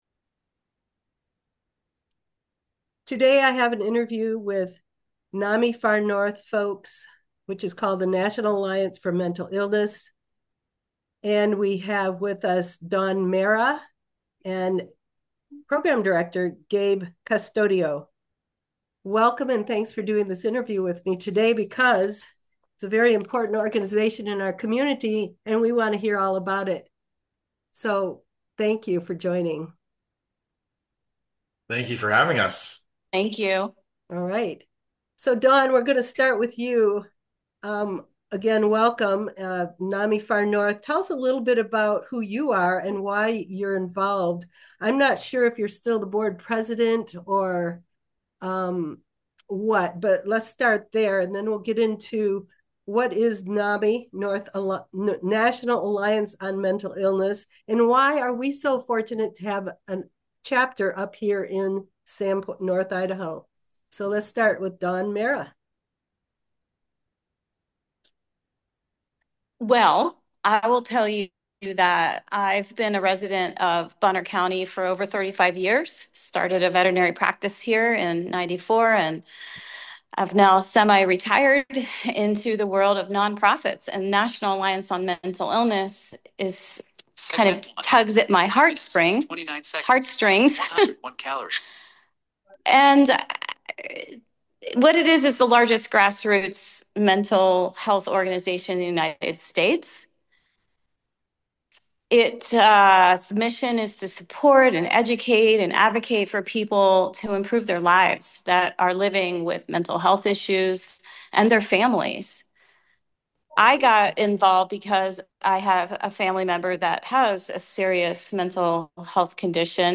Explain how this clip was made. NAMI Far North, the local chapter of the National Alliance for Mental Illness, will be in the studio with updates on the activities of the organization and the Sand Creek Clubhouse, a preview some upcoming events, and a general discussion on mental health now in our area.